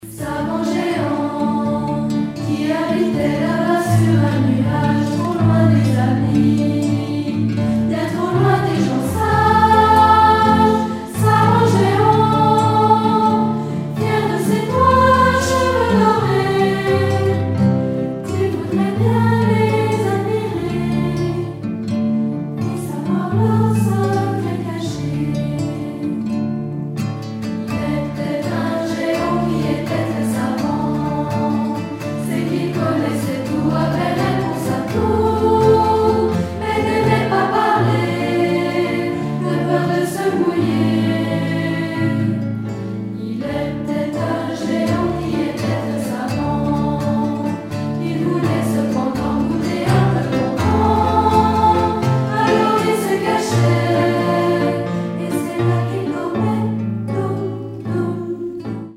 1991 - 1992 - Choeur d'enfants La Voix du Gibloux